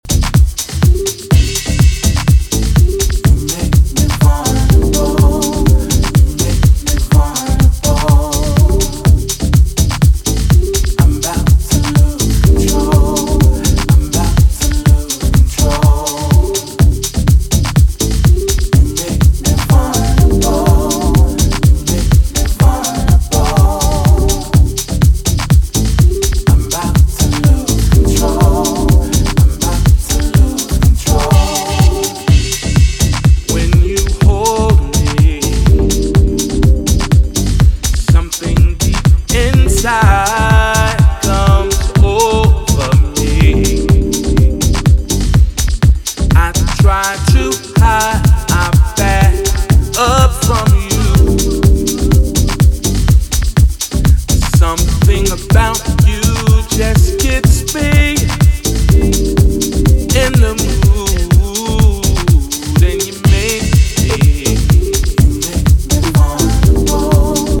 unreleased remix